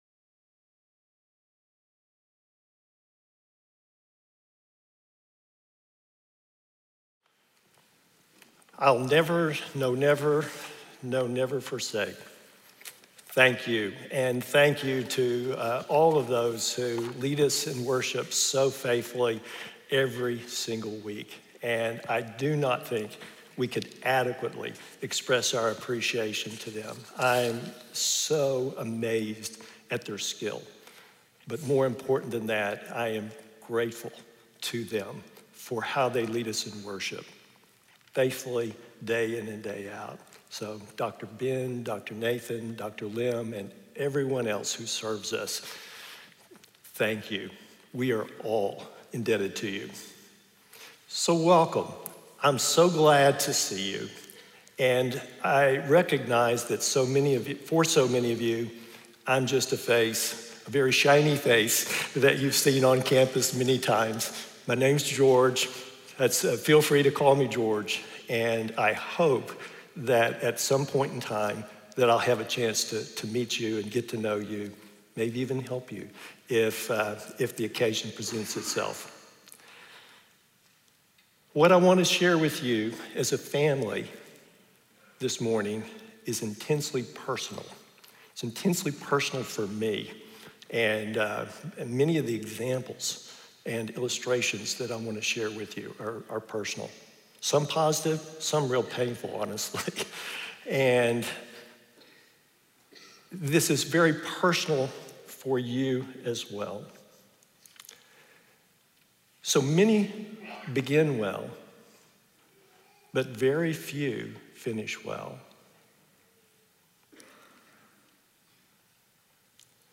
speaking on Psalm 138 in SWBTS Chapel